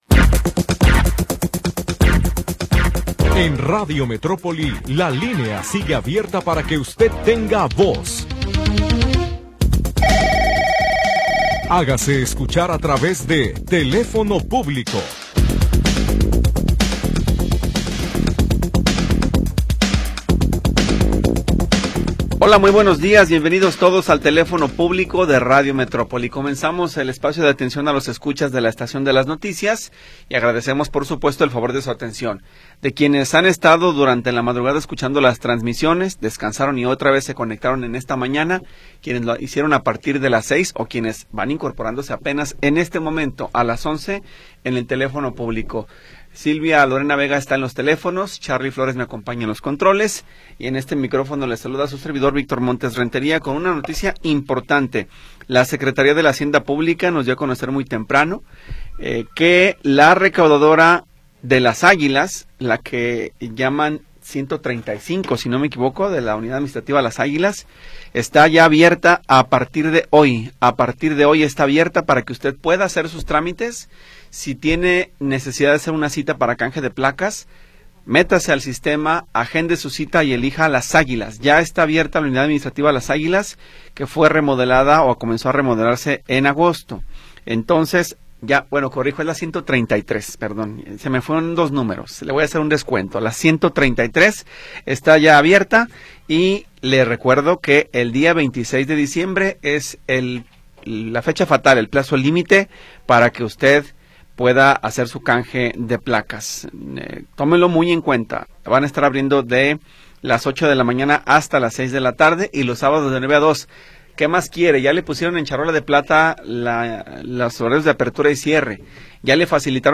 Programa transmitido el 3 de Diciembre de 2025.